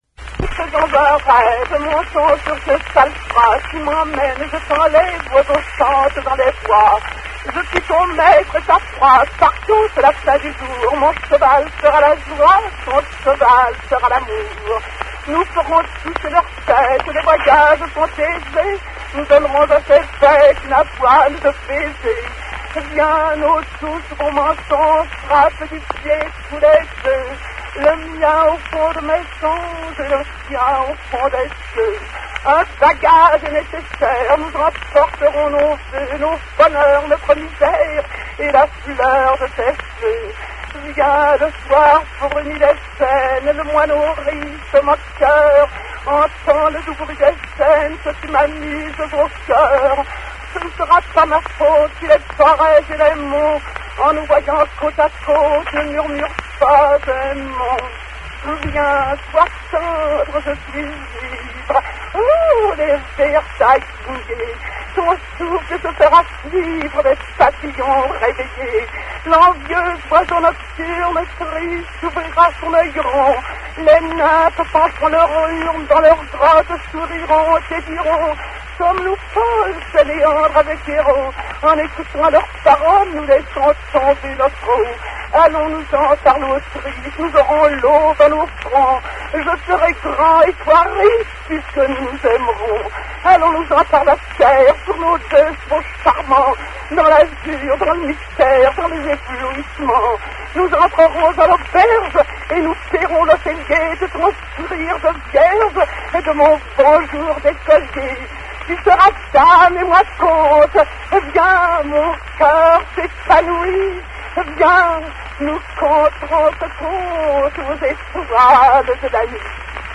Excerpt from the Victor Hugo play, Un peu de musique
Paris, 1902.